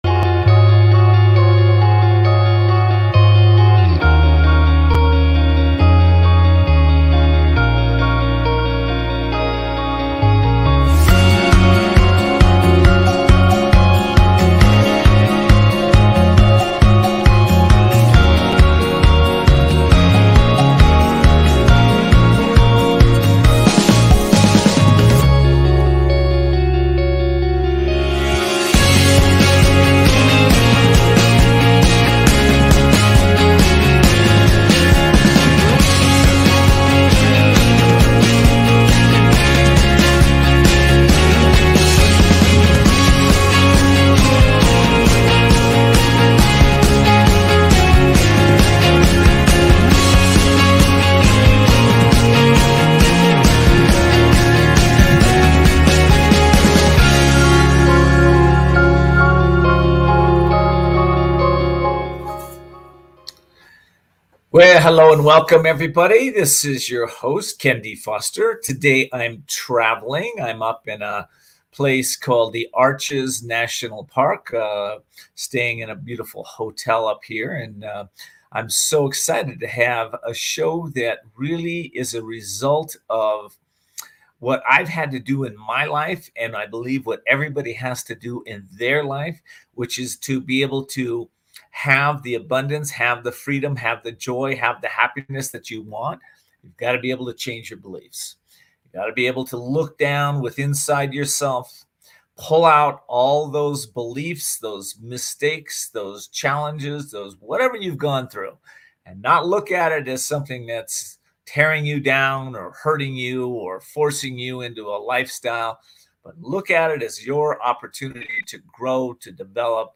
Please consider subscribing to this talk show.